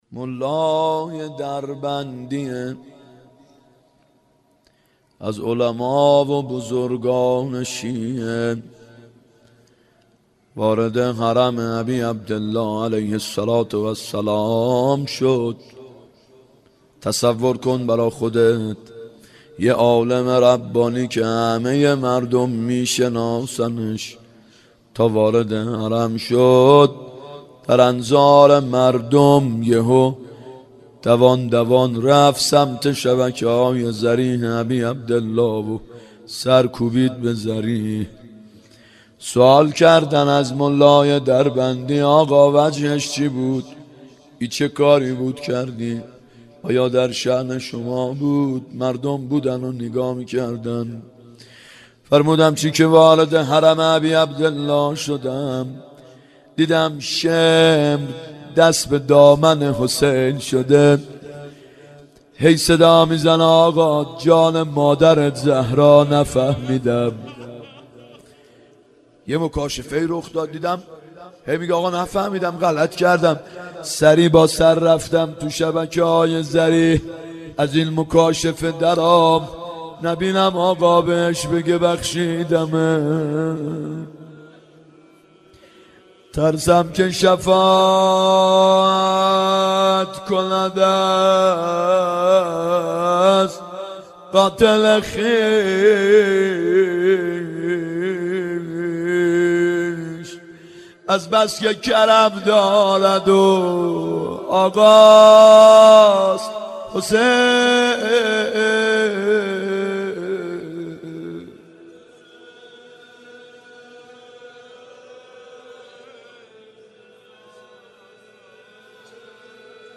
محرم 90 ( هیأت یامهدی عج)